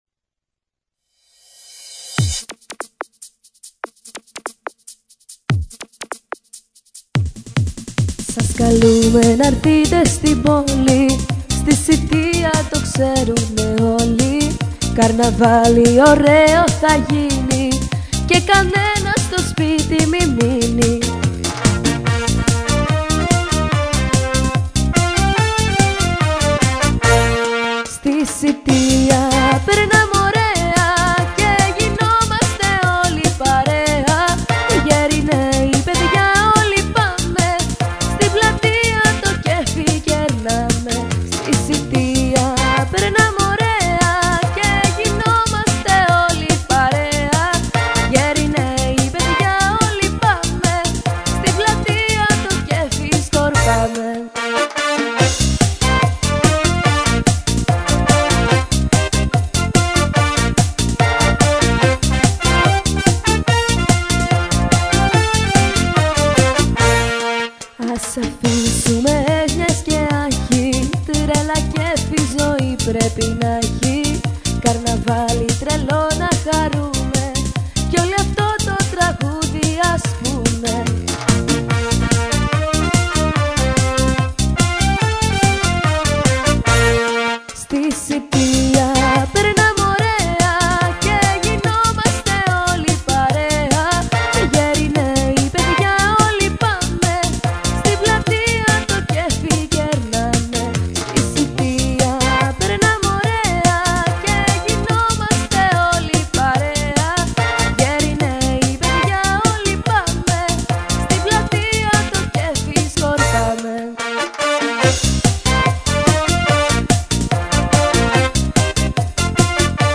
ερμηνεύτρια
Το τραγούδι ηχογραφήθηκε στο Studio